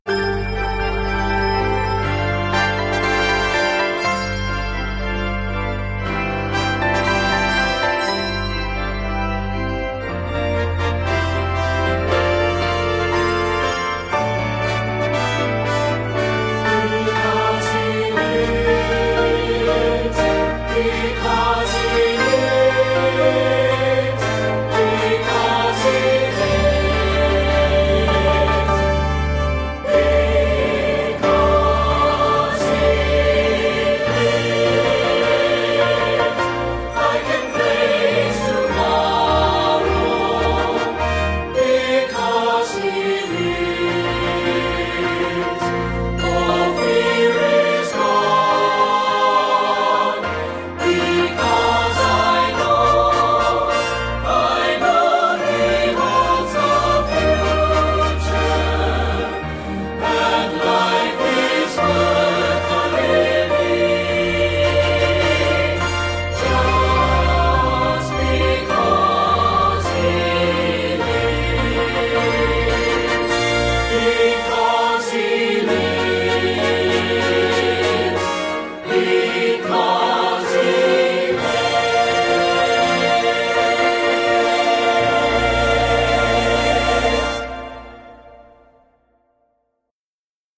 introit
Optional Brass, Timpani and Handbells add to the glory.